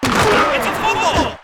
Football Device Disconnect
One of the other sound packages is called Football and I could have used its connect and disconnect sounds, but they fit in that category of cute sounds I warned about.
FB_Device_Disconnect_01St.wav